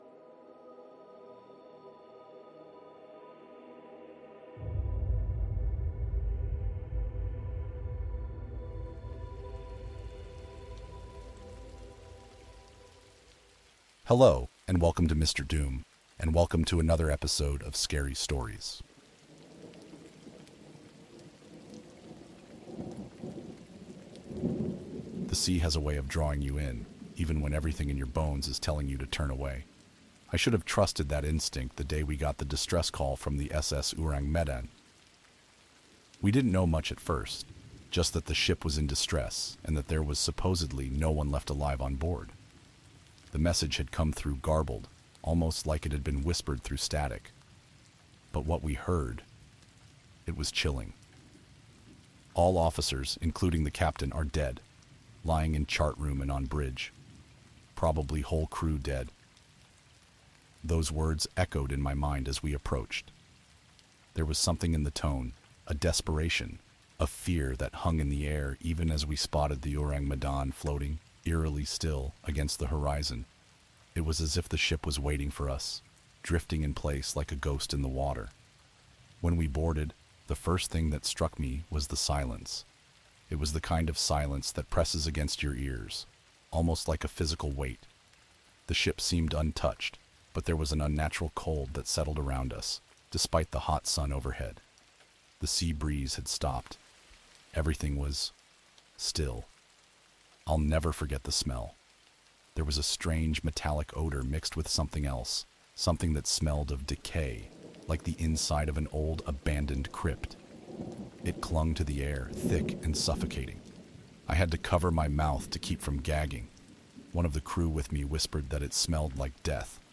Our scary stories podcast delivers the best in horror narration, featuring true scary stories, creepy encounters, and paranormal experiences. Whether you’re into ghost stories, Reddit horror, or disturbing real-life events, our podcast has you covered.